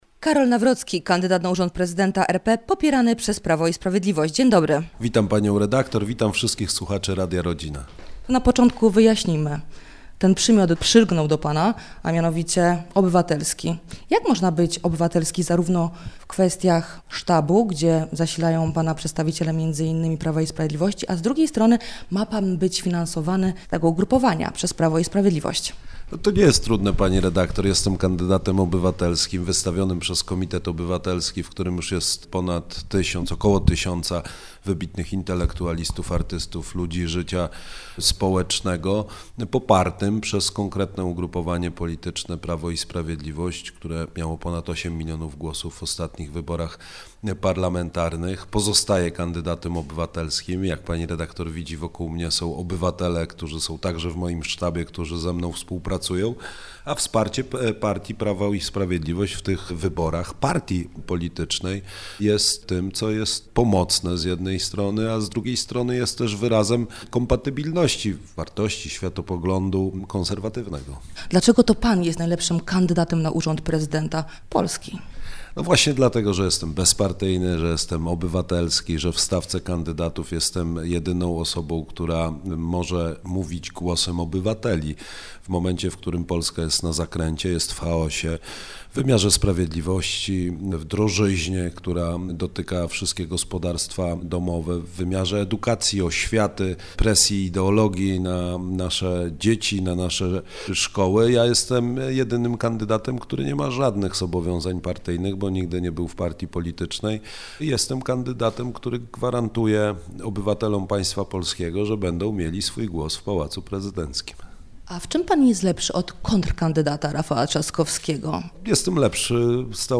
Karol Nawrocki – kandydat na urząd prezydenta popierany przez PiS był gościem Radia Rodzina. Rozmawialiśmy o patriotyzmie, kierunku polskiej edukacji, redukcji liczby godzin religii, światopoglądzie. „Porannego Gościa” zapytaliśmy także, dlaczego to on byłby najlepszym prezydentem Polski.